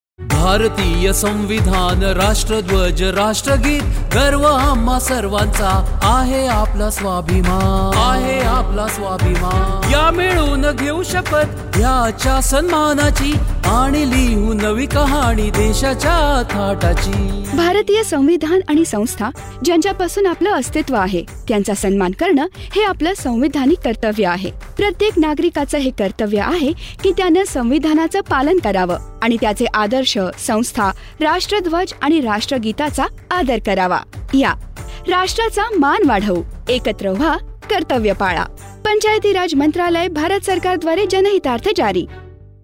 146 Fundamental Duty 1st Fundamental Duty Abide by the Constitution and respect National Flag and National Anthem Radio Jingle Marathi